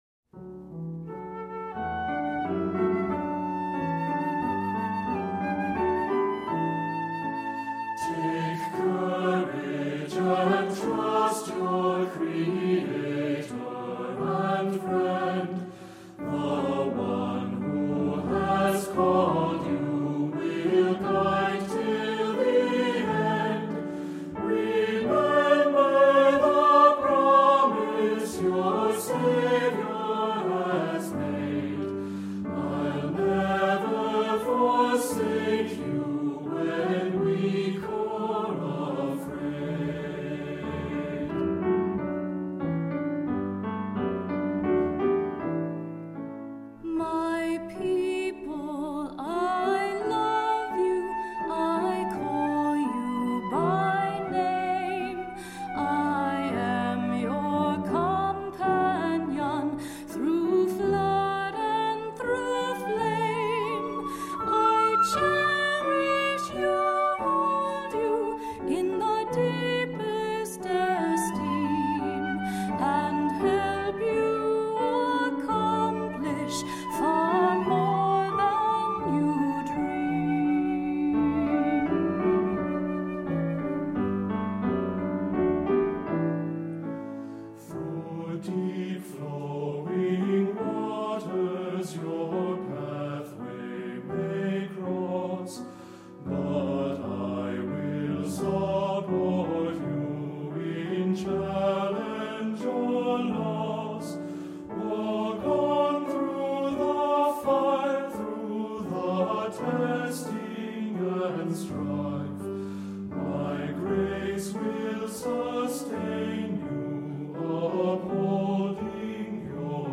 Voicing: Unison; Descant; Soloist; Assembly